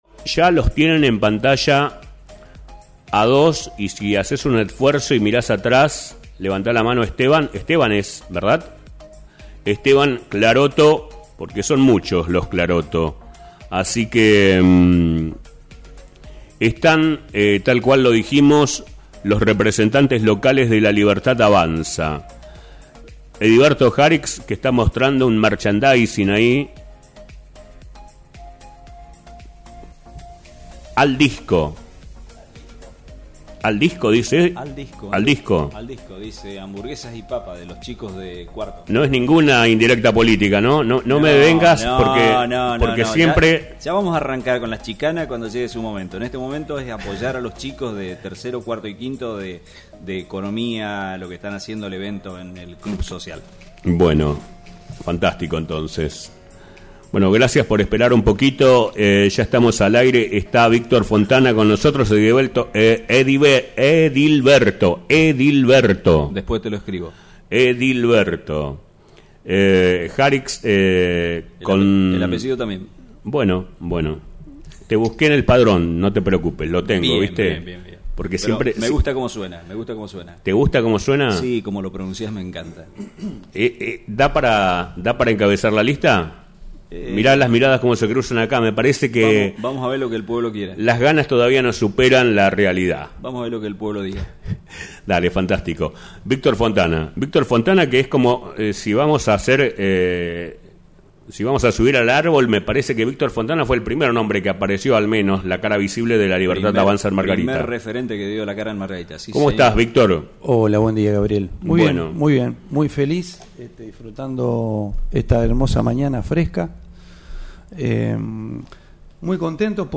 En su paso por el streaming de FMX Margarita
Tres voces, un mismo rumbo: